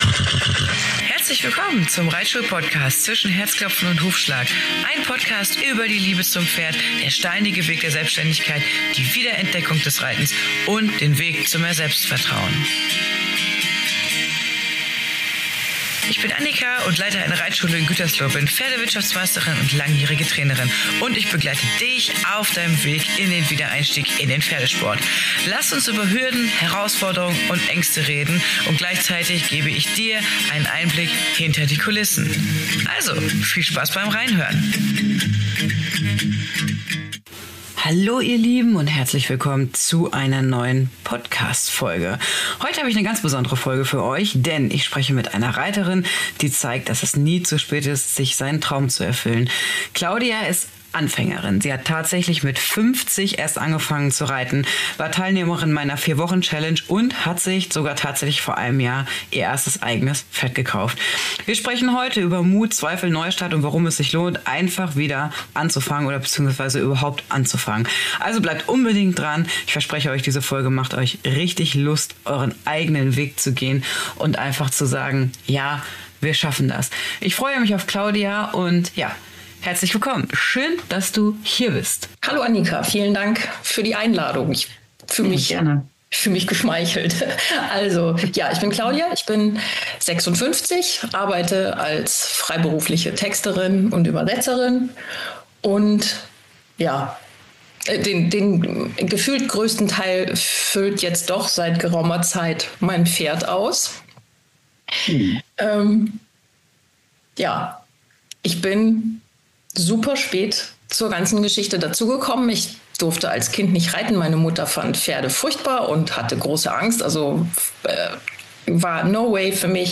Gasttalk